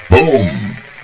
Bboom.ogg